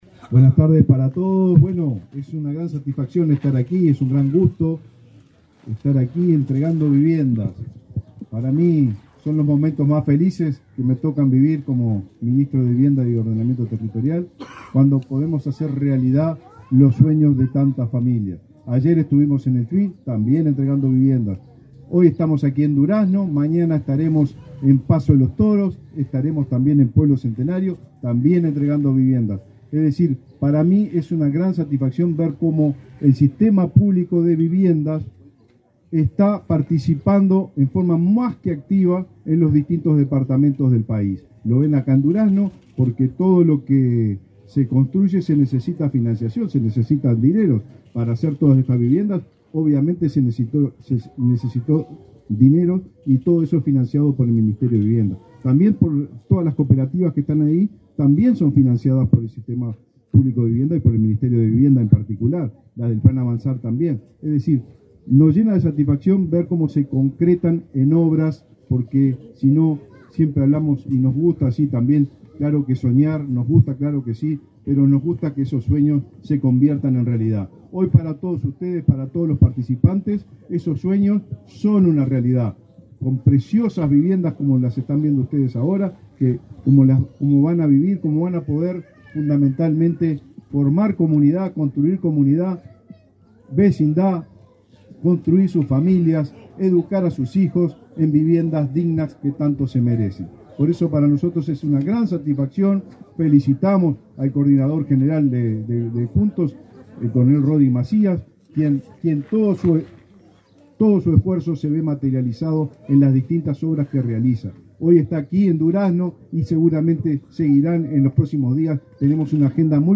Declaraciones del ministro de Vivienda, Raúl Lozano